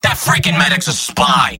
Robot-filtered lines from MvM. This is an audio clip from the game Team Fortress 2 .
{{AudioTF2}} Category:Scout Robot audio responses You cannot overwrite this file.